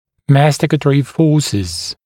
[‘mæstɪkətərɪ ‘fɔːsɪz][‘мэстикэтэри ‘фо:сиз]силы, возникающие при жевании